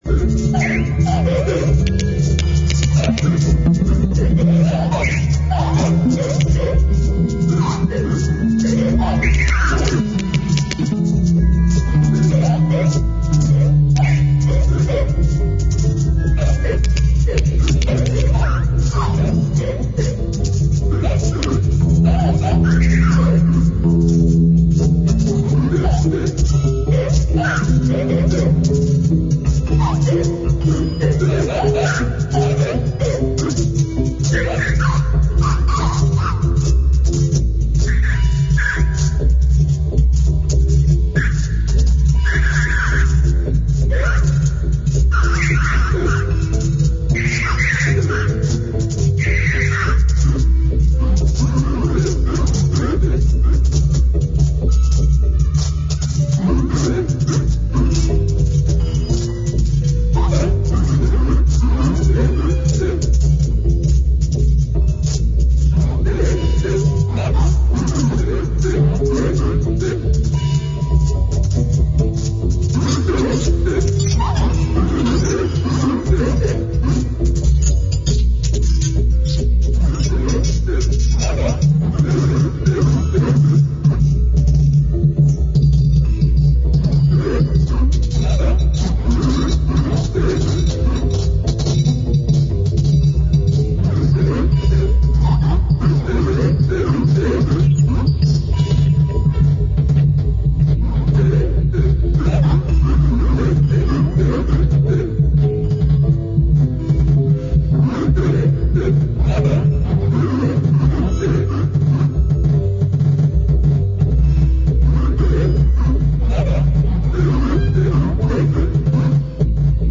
Dobrodošla na svobodni medmedijski jam-session!